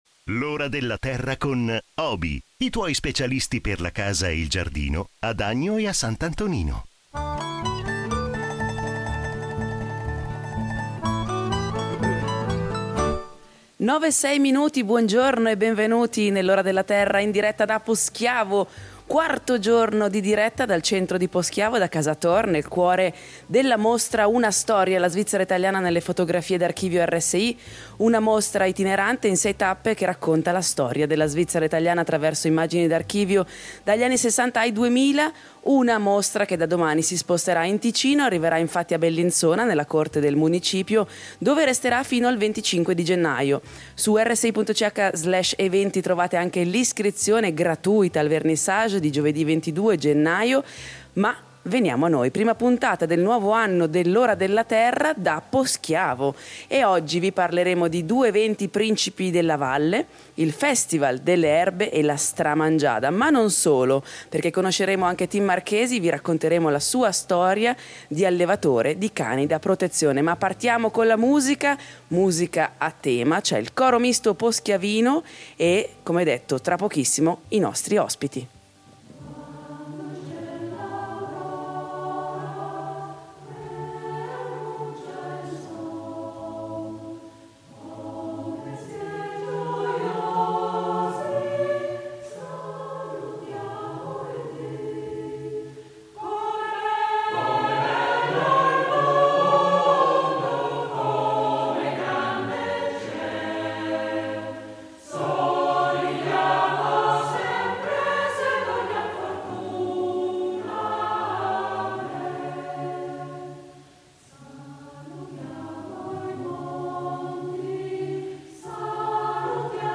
Naturalmente non mancheranno gli esperti de L’Ora della Terra che risponderanno al pubblico da casa.